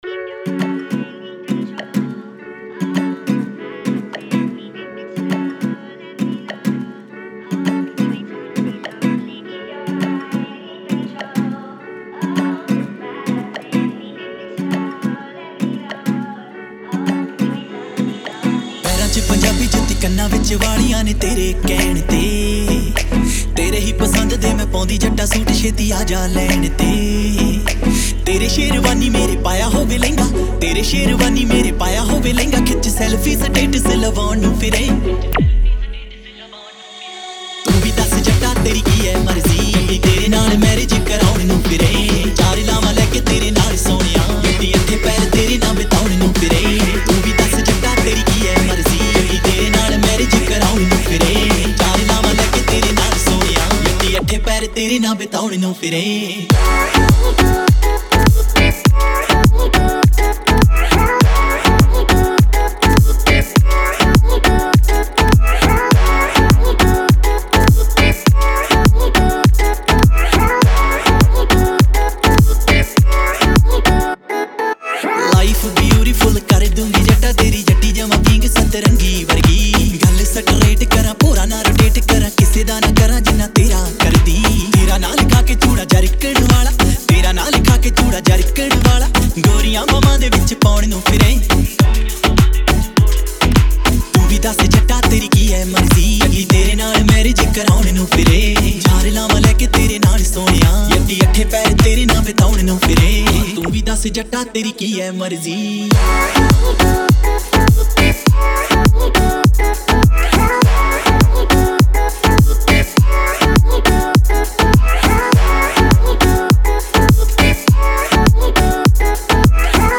Punjabi DJ Remix Songs